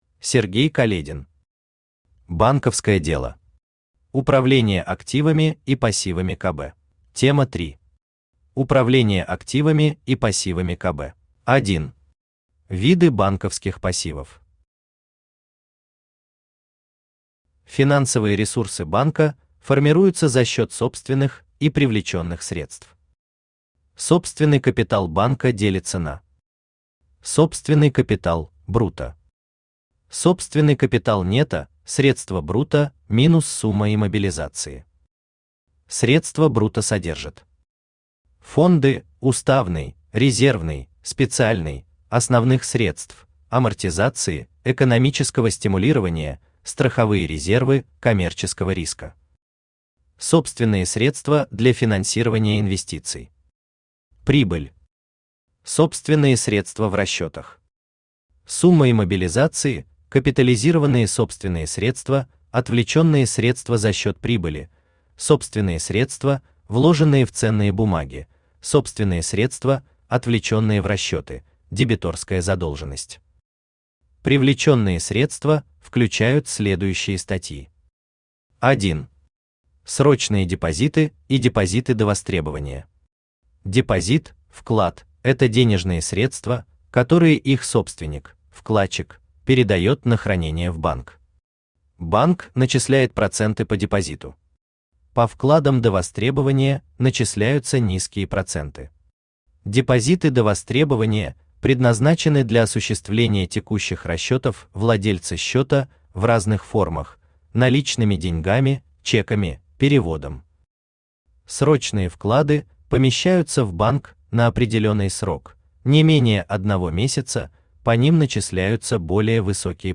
Аудиокнига Банковское дело. Управление активами и пассивами КБ | Библиотека аудиокниг
Управление активами и пассивами КБ Автор Сергей Каледин Читает аудиокнигу Авточтец ЛитРес.